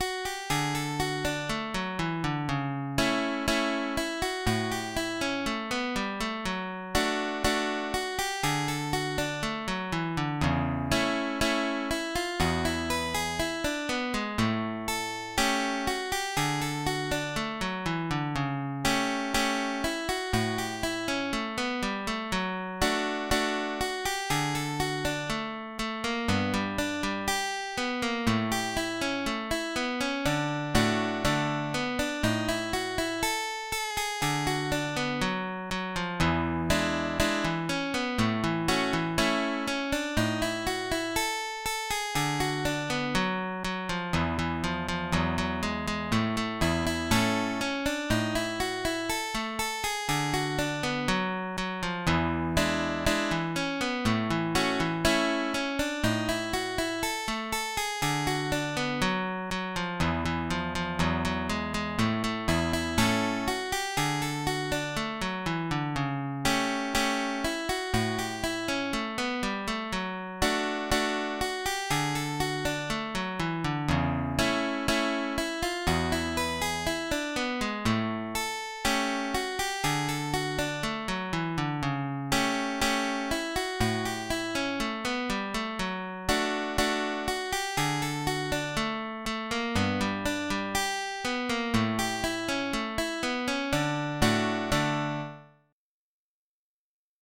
Gavotta***